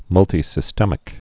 (mŭltē-sĭ-stĕmĭk, -stēmĭk, -tī-)